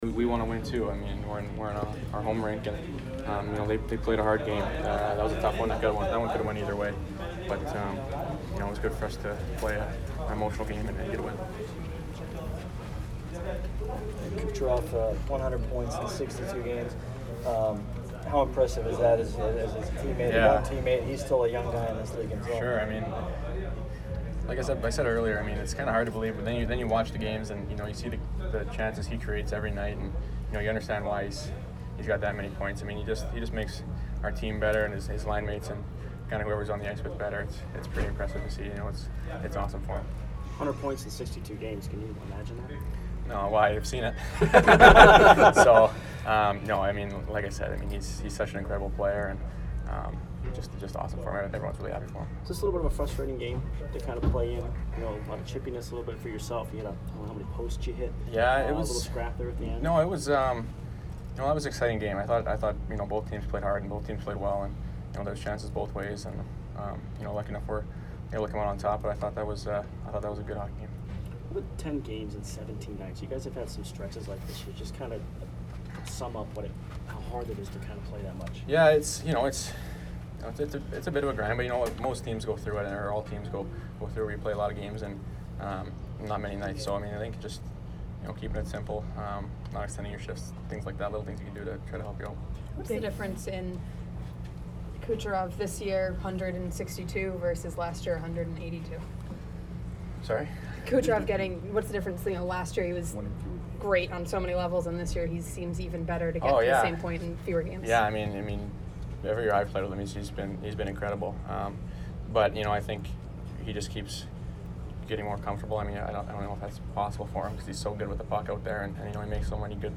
Brayden Point post-game 2/21